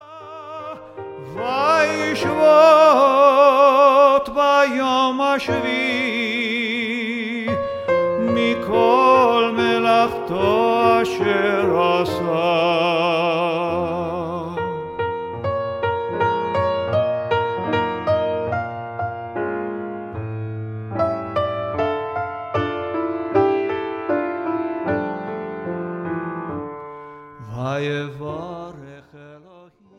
simultaneously exciting, melodic, and engaging